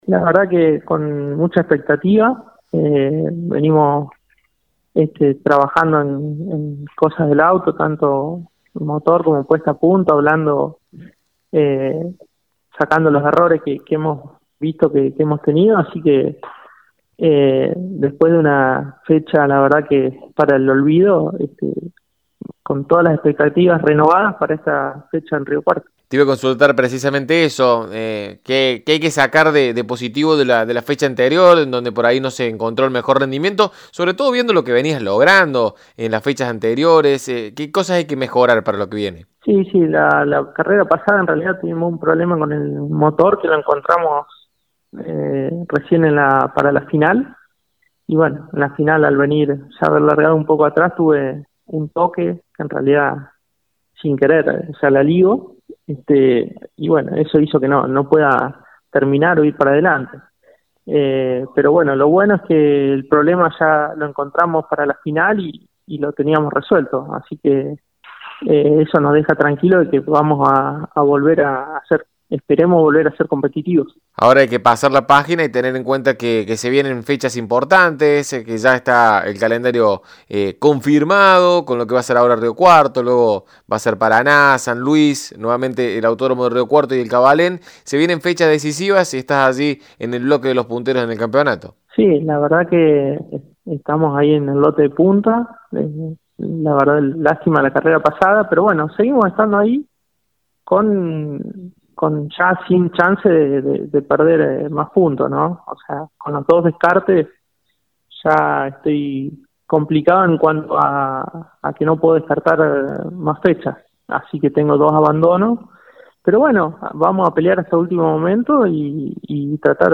En diálogo con Poleman Radio, nos narró cómo es su preparación y lo que espera para esta competencia: